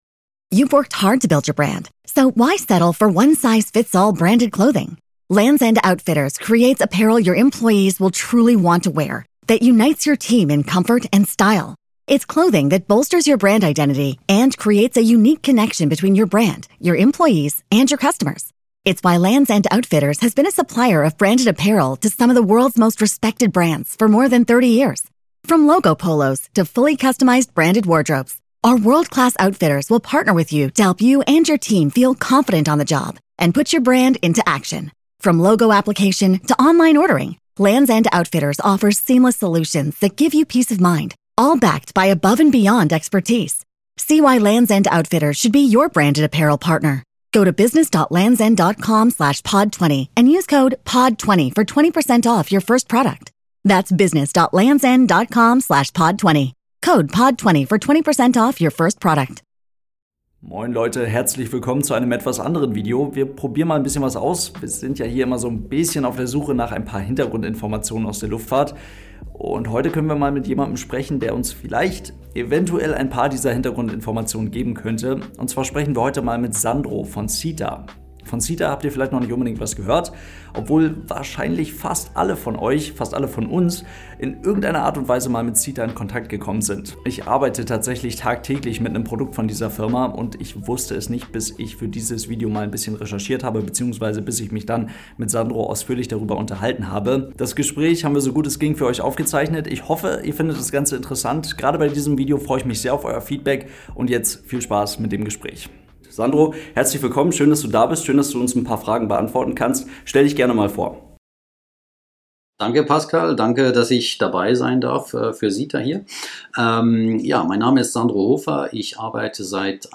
SITA im Gespräch! AeroNews ~ AeroNewsGermany Podcast